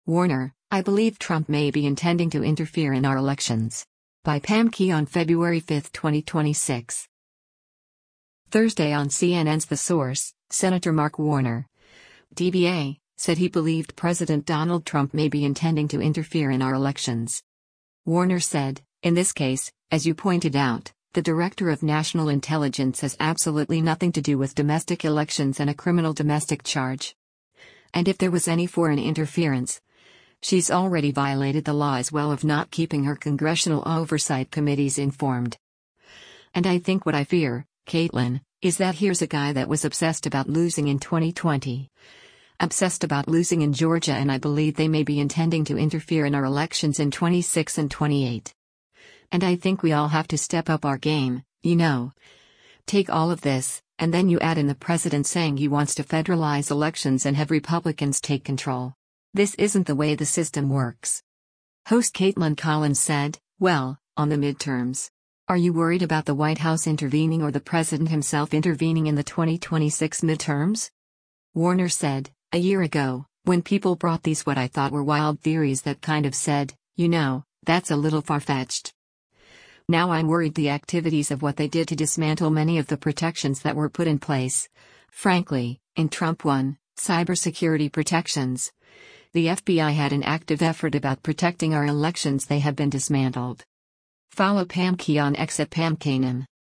Thursday on CNN’s “The Source,” Sen. Mark Warner (D-VA) said he believed President Donald Trump “may be intending to interfere in our elections.”